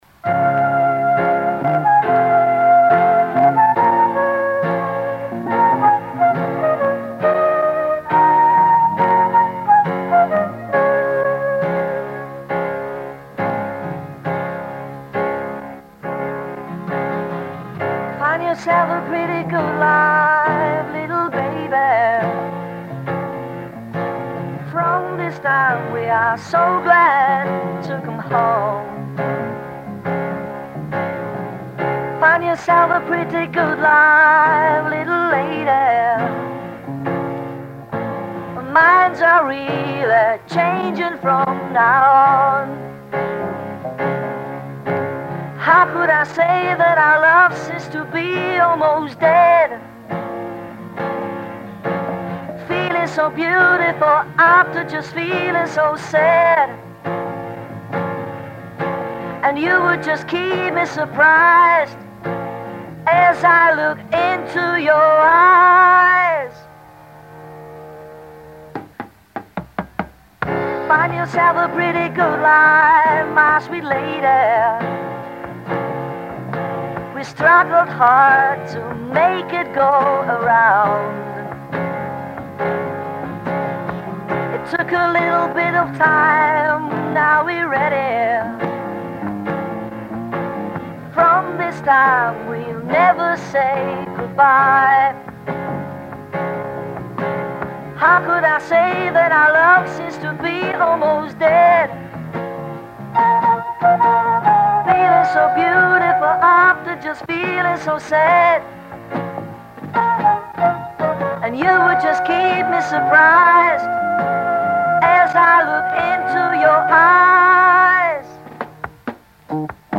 04 - SOUL